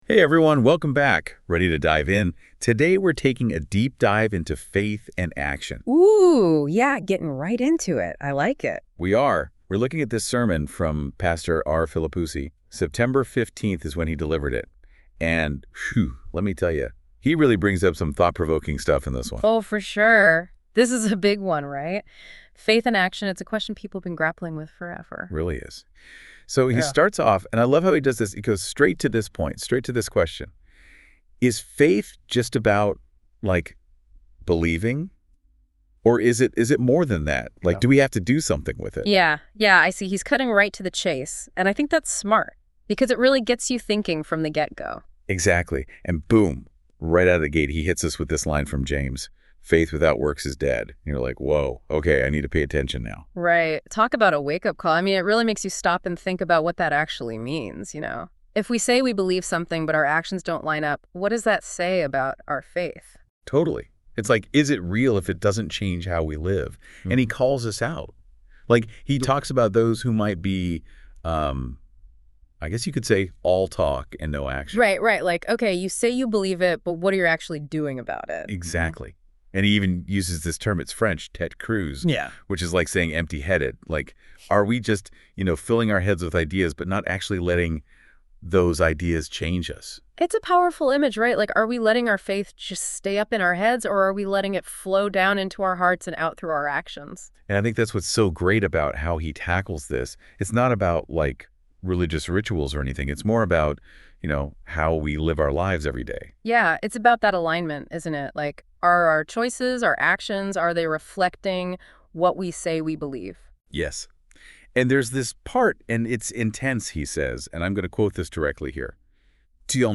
Prédication du 15 septembre 2024.pdf (44.32 Ko) AUDIO extraits culte du 15 septembre 2024.mp3 (85.18 Mo)
ORGUE